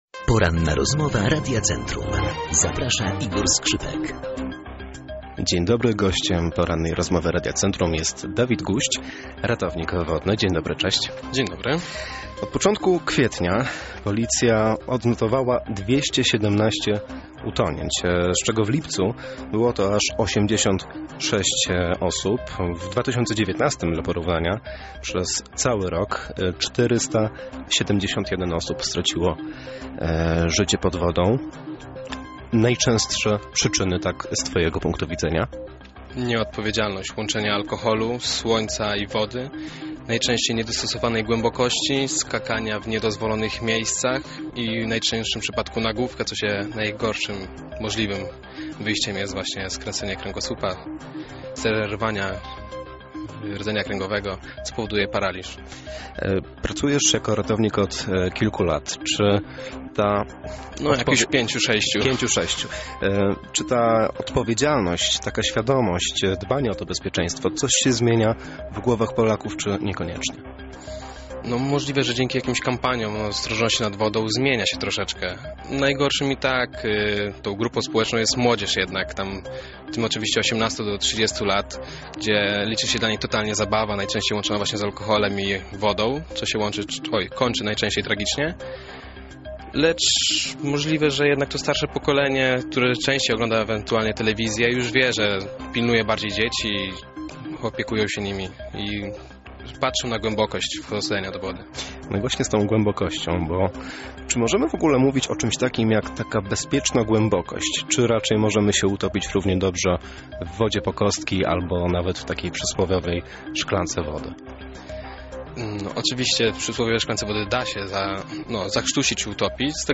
Pełną rozmowę z ratownikiem wodnym znajdziecie poniżej. Usłyszycie tam między innymi jak się zachować, gdy znajdziemy się w kryzysowej sytuacji, oraz czy możemy pomóc komuś kto tonie:
ROZMOWA.03.08.mp3